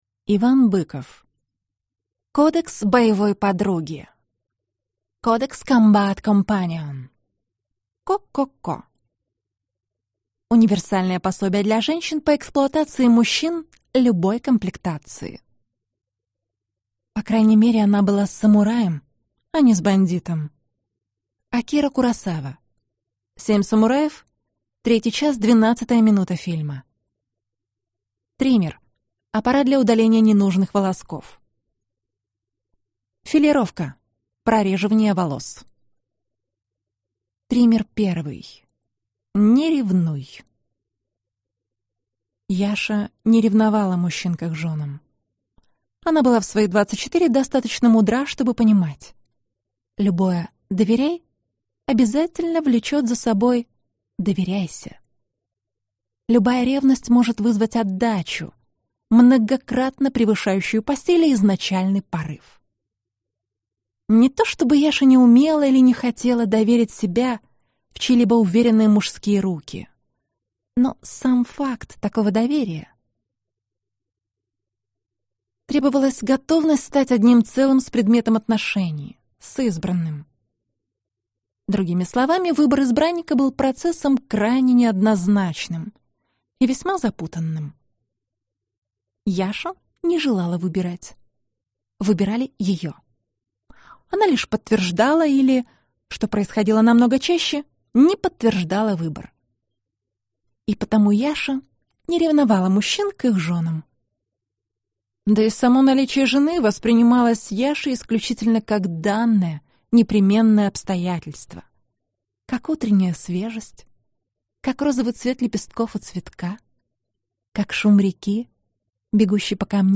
Аудиокнига Кодекс боевой подруги | Библиотека аудиокниг